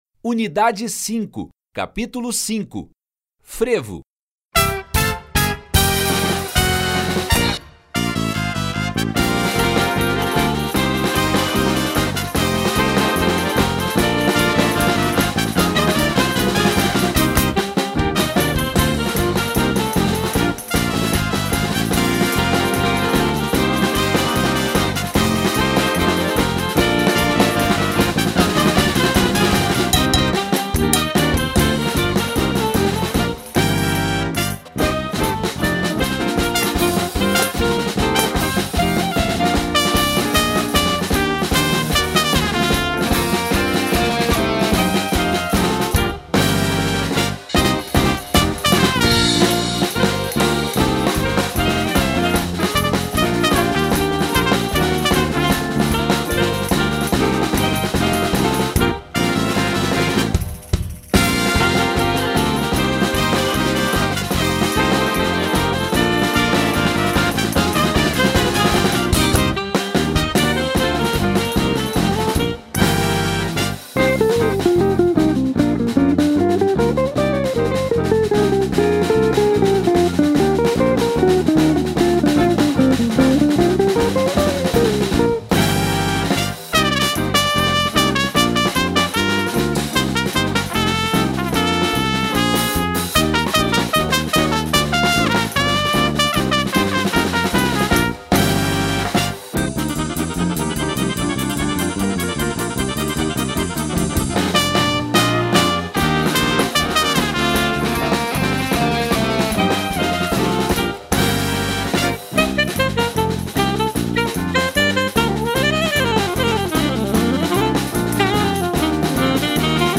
Frevo